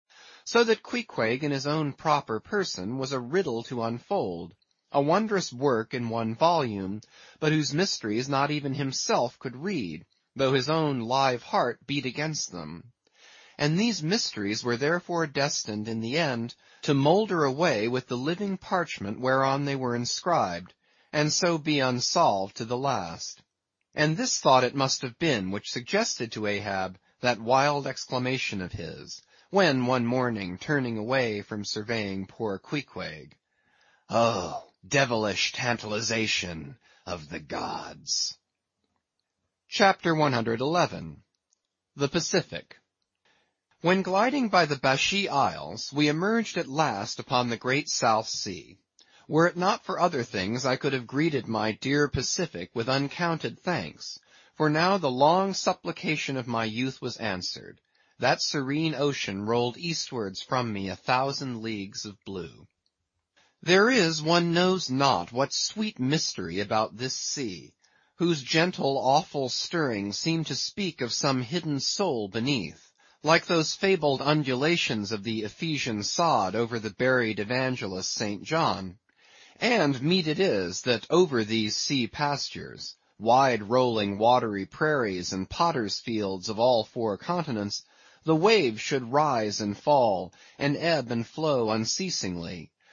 英语听书《白鲸记》第910期 听力文件下载—在线英语听力室